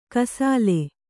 ♪ kasāle